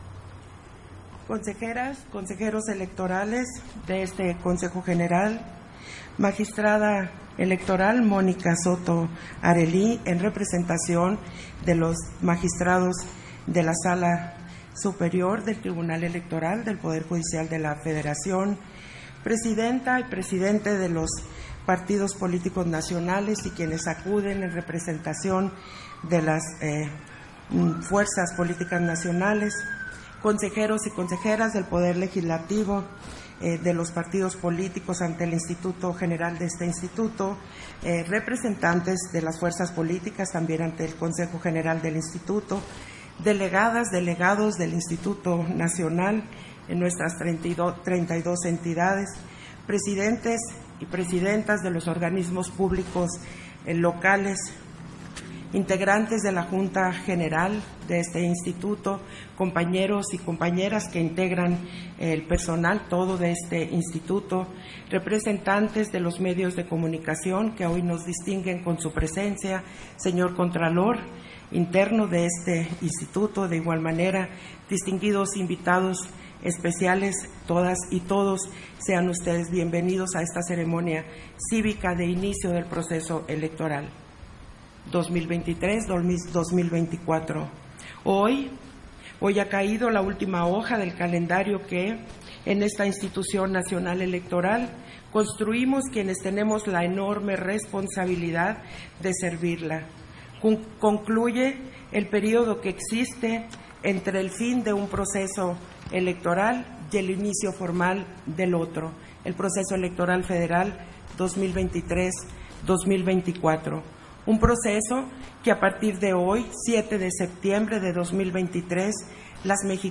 070923_AUDIO_INTERVENCIÓN-CONSEJERA-PDTA.-TADDEI-CEREMONIA-CÍVICA - Central Electoral